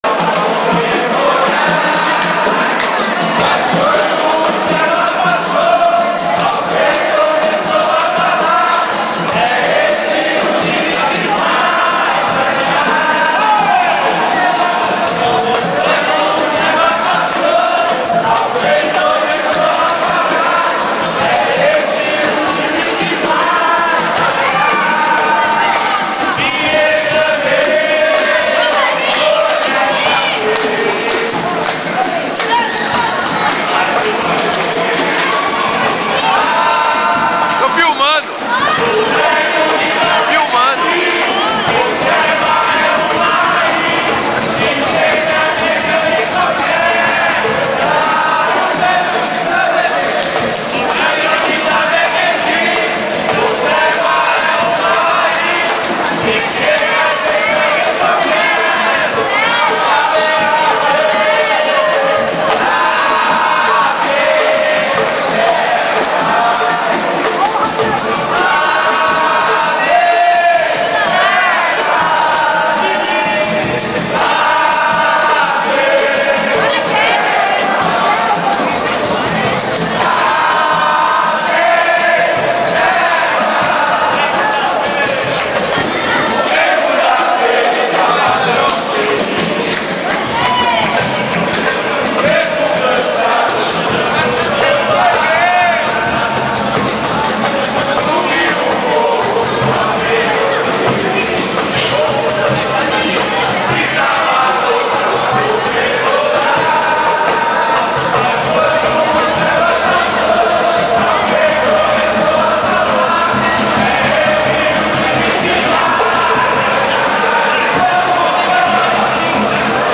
samba_serva_mp3.MP3